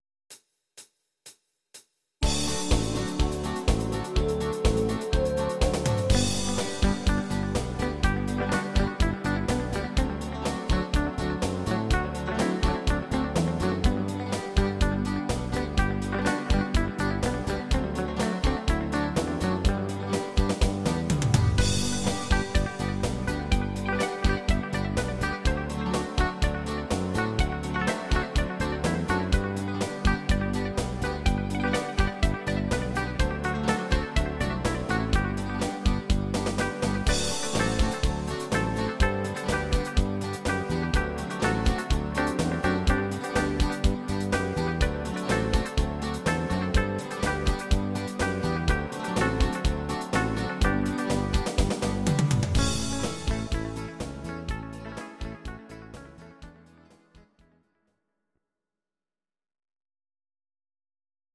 These are MP3 versions of our MIDI file catalogue.
Please note: no vocals and no karaoke included.
Gitarre